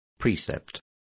Complete with pronunciation of the translation of precepts.